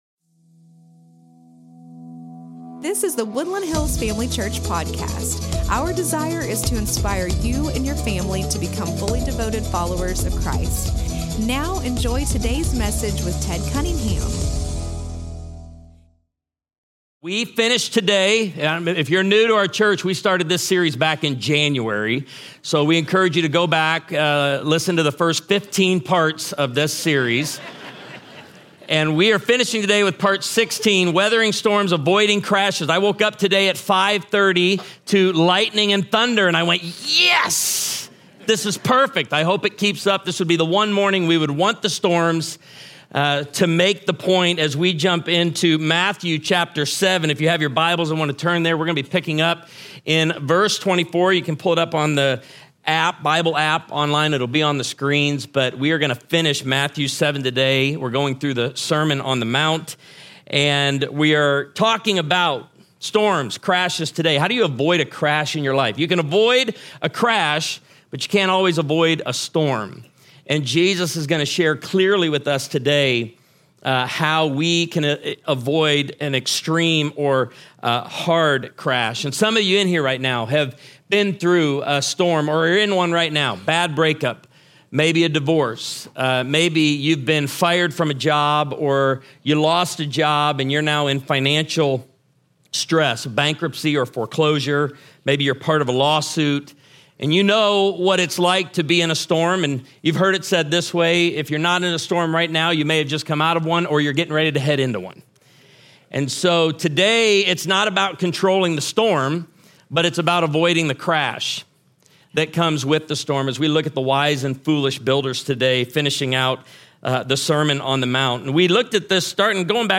The Greatest Sermon Ever (Part 16)